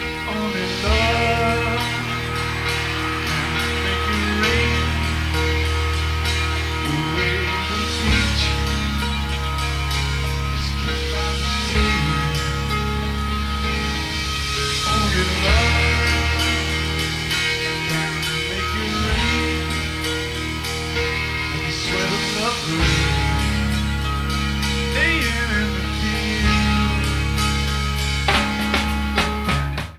Pre-FM Radio Station Reels
Needs remastering.